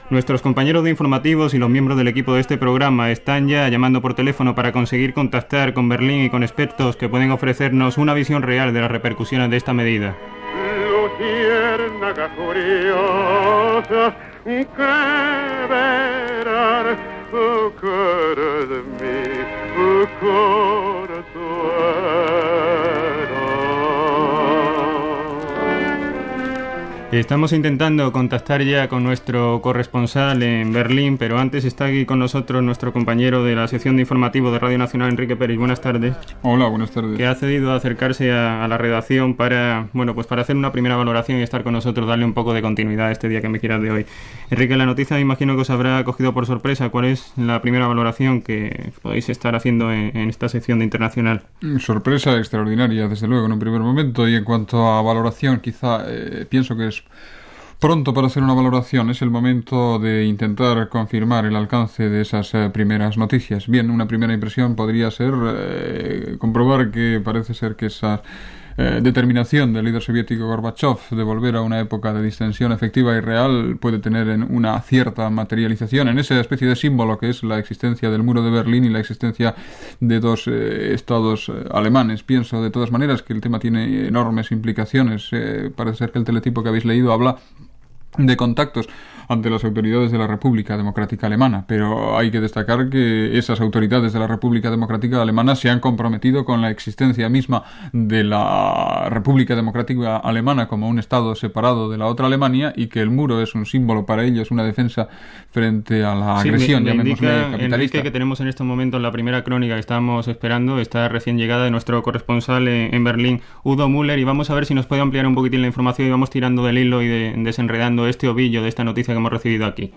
Ficció periodística sobre la caiguda del mur de Berlín (un parell d'anys abans que passés aquest fet el 9 de novembre de 1989)
Ficció
FM